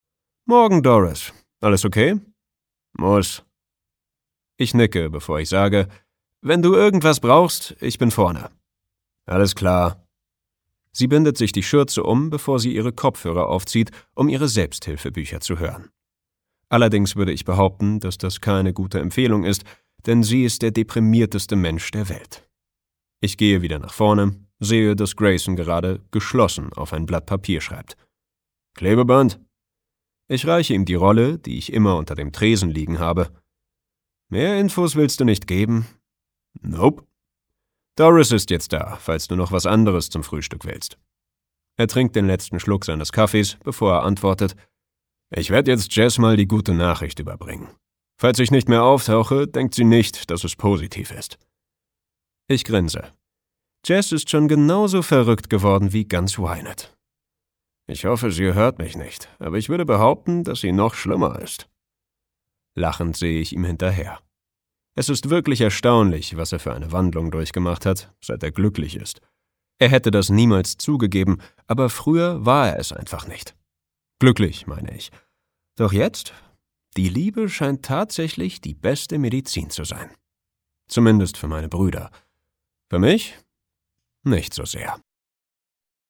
Infos zum Hörbuch